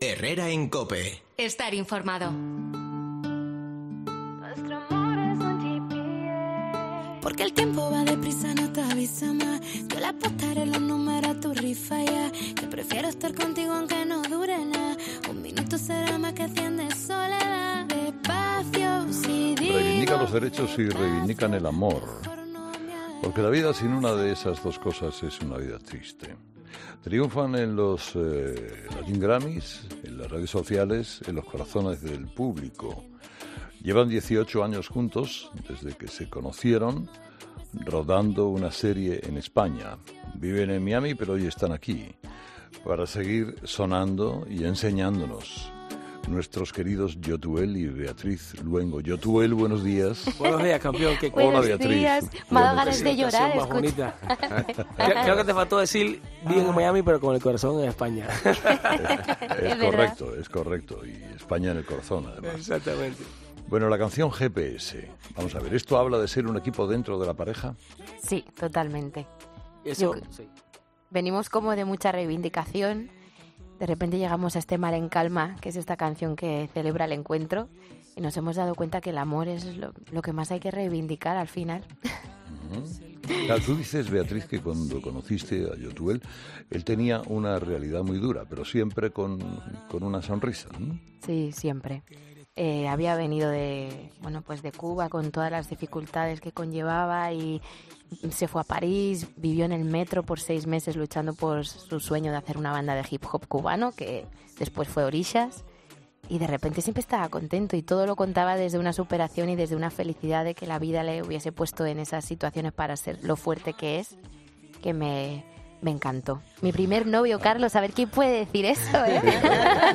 Durante su conversación con Carlos Herrera, Beatriz también ha querido recordar la historia de su marido .